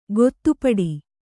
♪ gottupaḍi